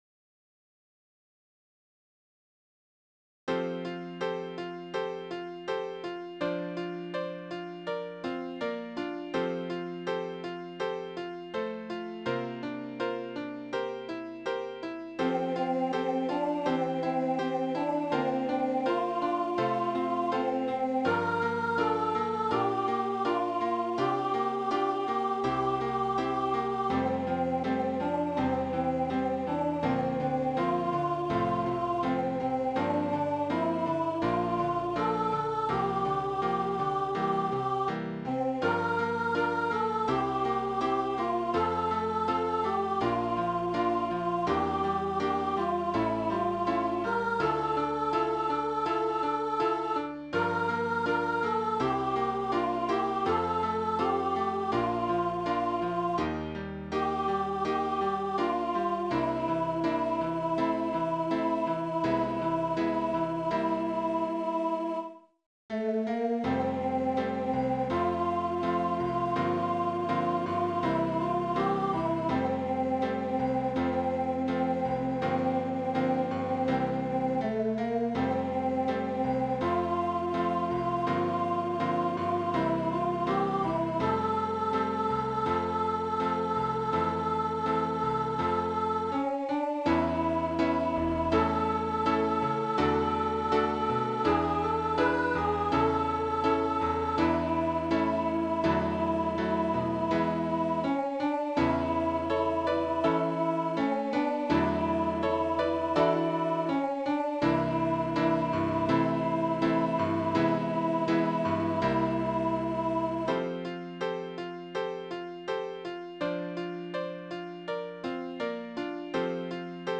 Medium Voice/Low Voice